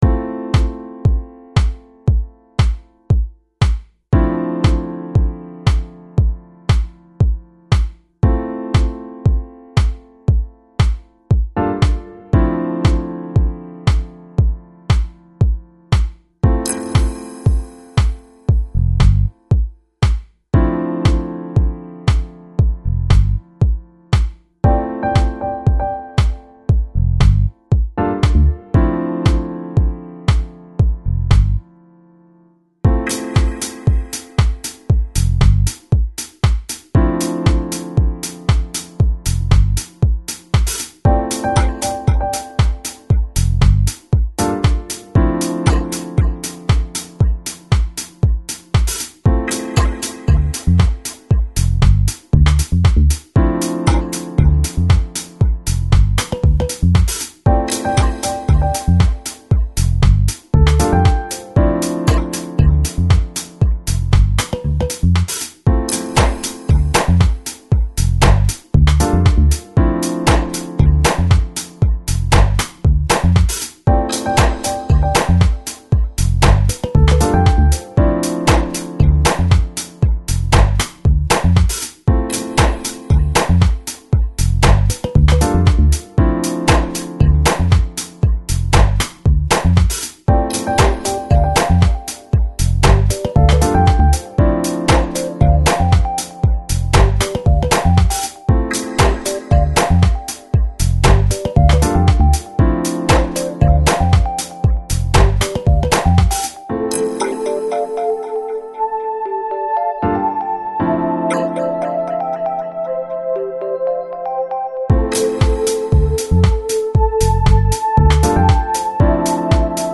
Жанр: Electronic, Downtempo, Lounge, Chill Out